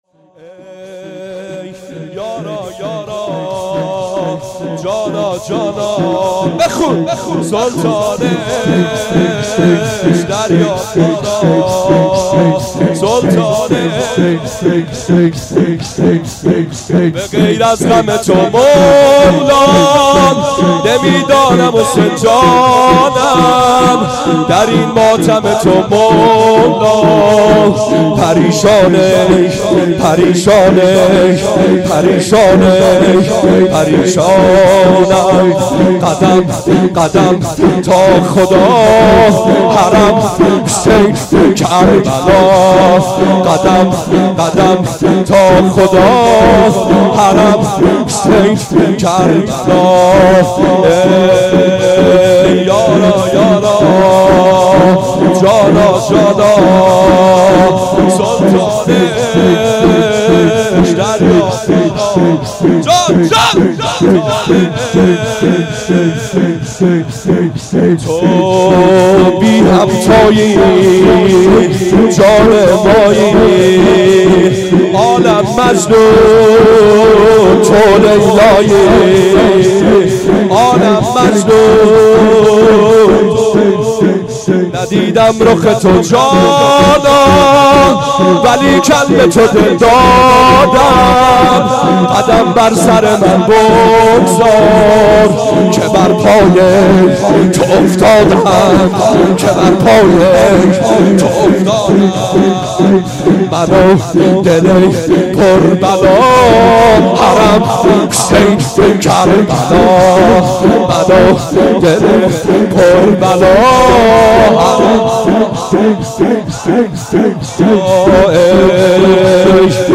سینه زنی شور | یارا یارا
مداحی جدید مسجد المهدی